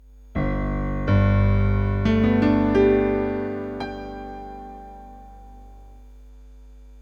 Here is an example at the high end of what I would describe as a “moderate amount”
The buzz is a recording of my finger on the end of a jack plug straight into the sound card).